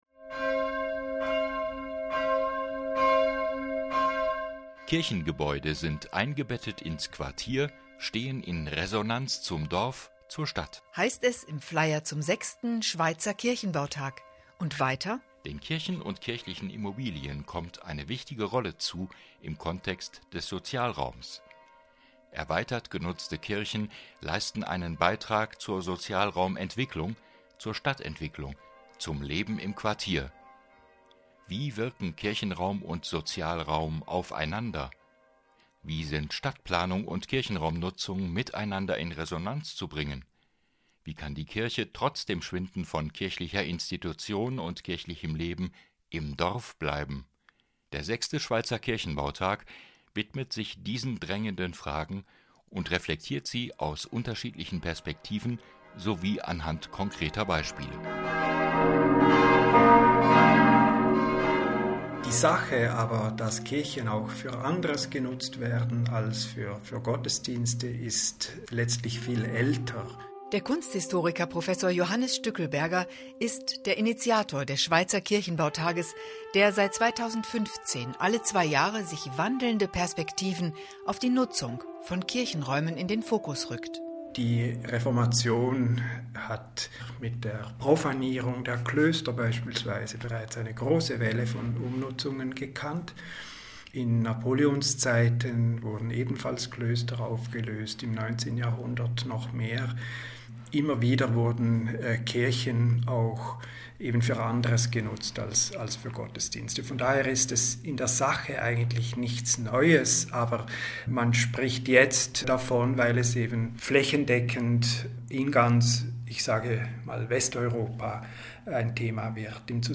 Le rapport de conférence (audio) donne en 30 minutes un aperçu du sujet de la sixième Journée suisse du patrimoine religieux 2025, propose des extraits des exposés, interroge les intervenants et fournit des informations générales sur l'institution.